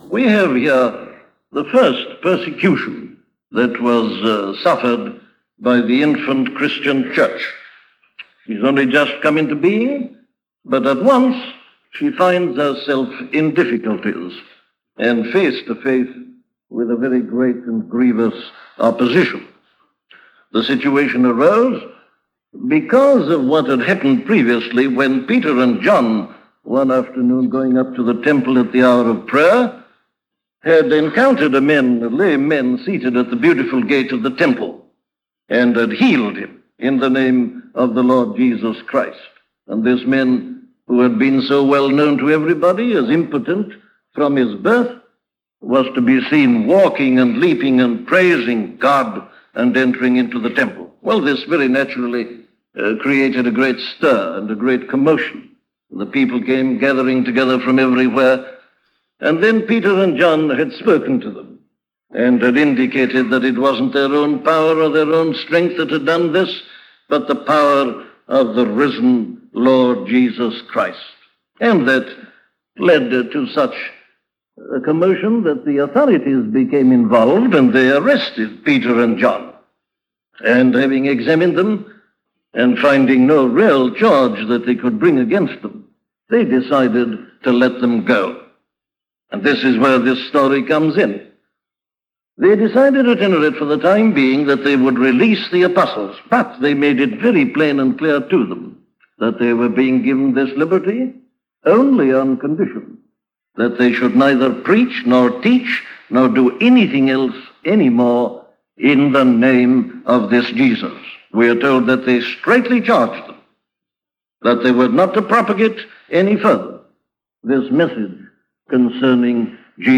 Prayer - a sermon from Dr. Martyn Lloyd Jones